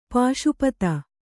♪ pāśupata